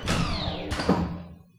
switch_6off.wav